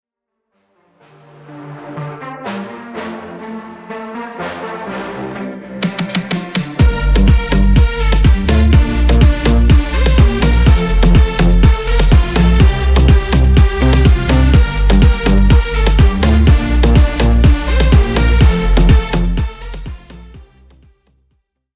DEMO OF THE INSTRUMENTAL WAV: